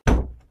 Play, download and share 08- Car Door Slams original sound button!!!!
08-car-door-slams.mp3